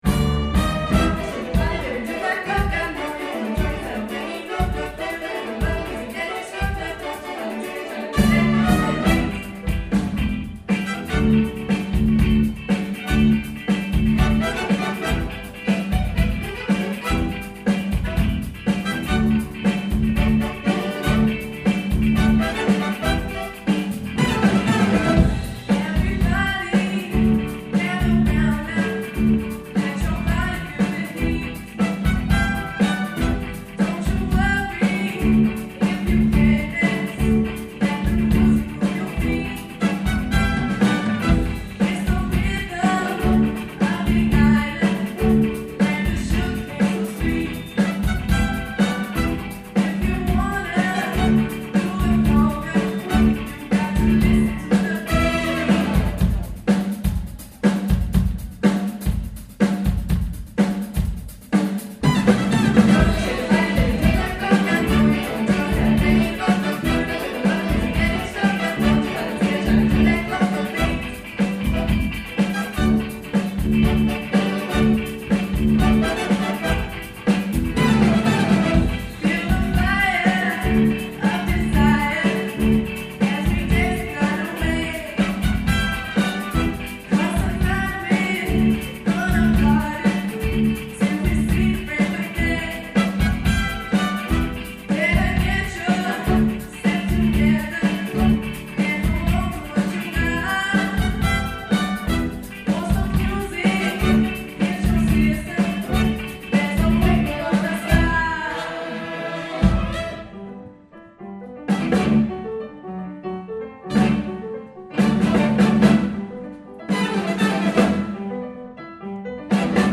Conga - Big Band